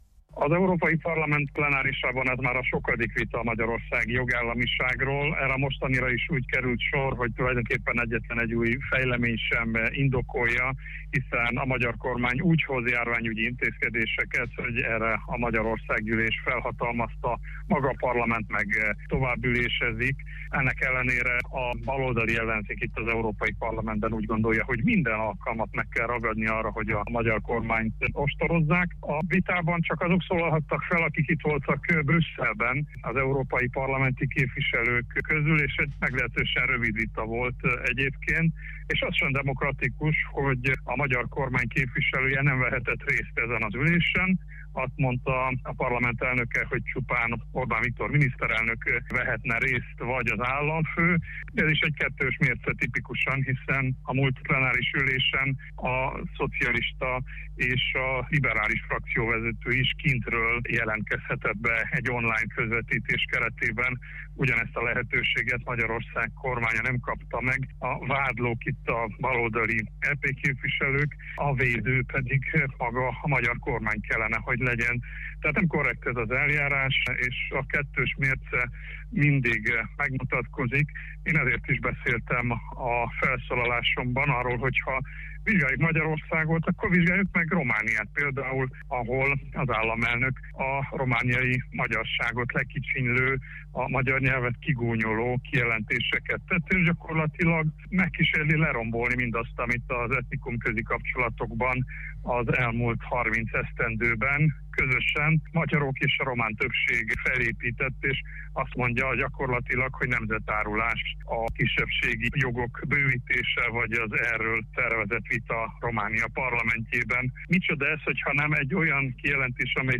A magyarországi jogállamiság helyzetéről tartottak ma plenáris ülést az Európai Parlamentben, ahol többek között Vincze Lóránt az RMDSZ európai parlamenti képviselője is felszólalt.